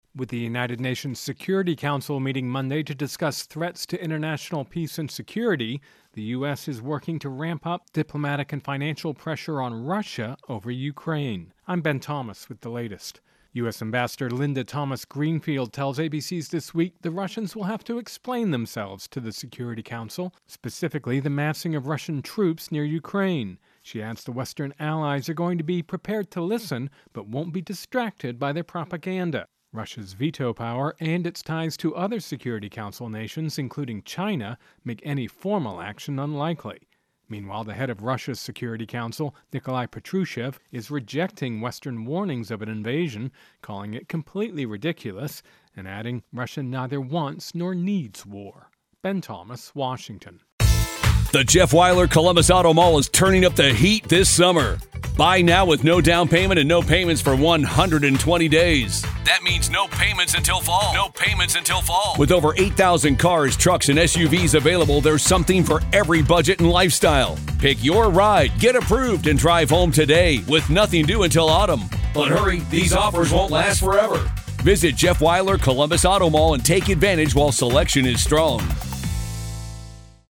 Intro and voicer "Russia Ukraine Tensions"